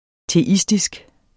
Udtale [ teˈisdisg ]